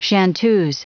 Main Entry: chan�teuse Pronunciation: \shan-ˈt�z, sh�ⁿ-ˈtə(r)z\